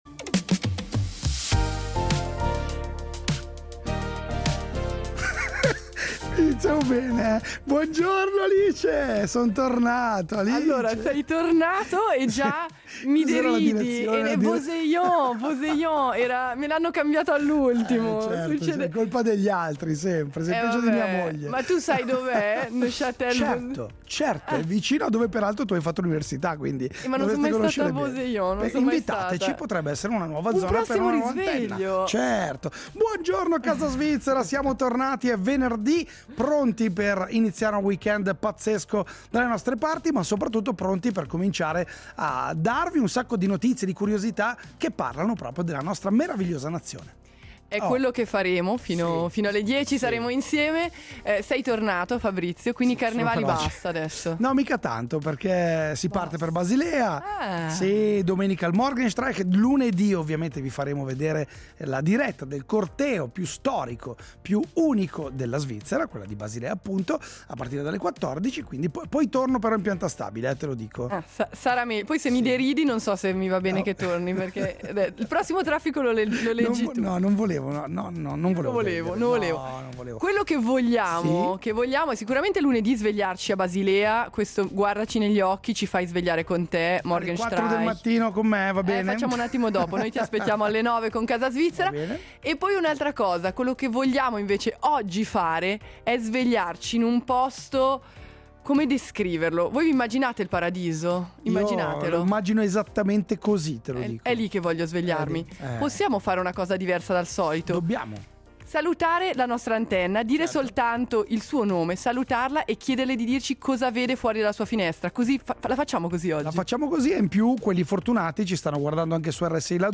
E poi musica dal vivo: il racconto di due giovani talenti formati al Conservatorio, che hanno scelto di creare un’orchestra da camera composta solo da professionisti, puntando su un repertorio intimo, suonato in spazi raccolti, dove ogni nota si sente e si vive.